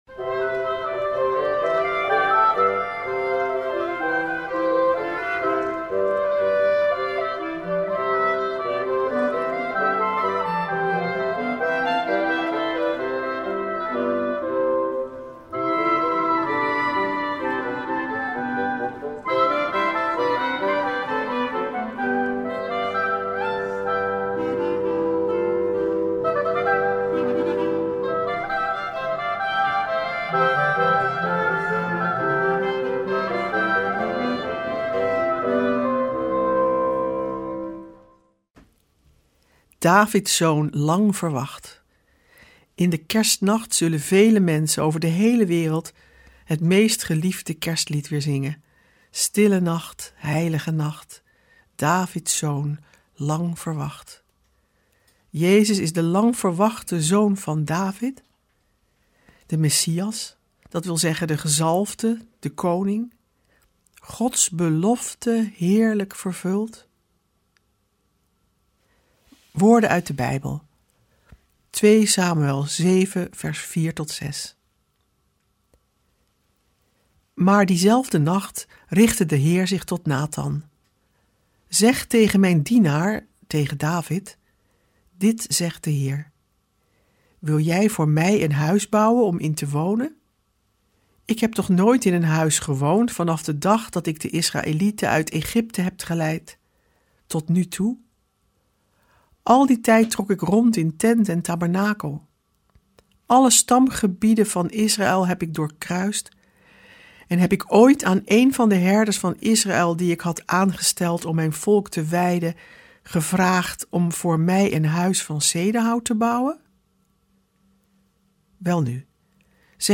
Ook dit jaar komen klassieke Bijbelteksten aan de orde, teksten die al eeuwen in de kerk juist in deze weken van Advent zijn gelezen. We verbinden ze met onze tijd en we luisteren naar prachtige muziek en poëzie.